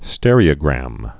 (stĕrē-ə-grăm, stîr-)